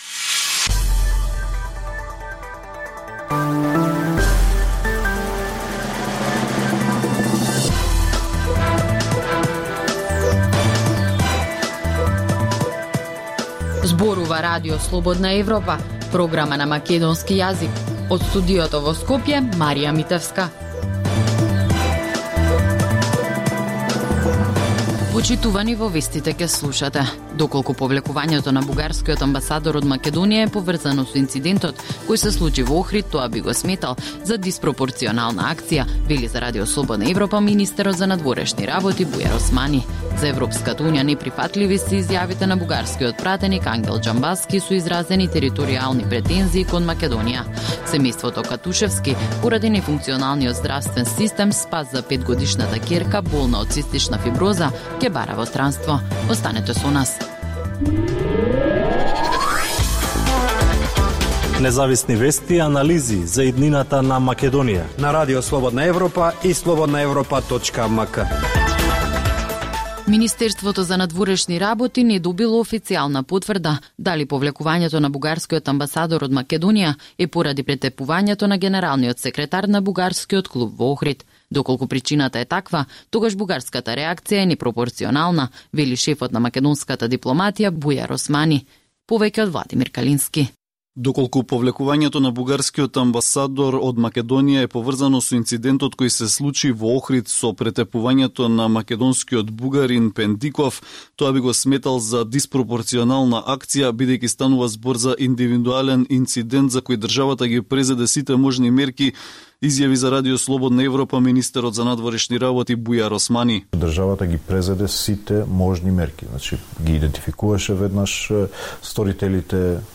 Утринска програма на Радио Слободна Европа од Студиото во Скопје. Во 15 минутната програма од понеделник до петок можете да слушате вести и прилози од земјата, регионот и светот. Во голем дел емисијата е посветена на локални настани, случувања и приказни од секојдневниот живот на граѓаните во Македонија.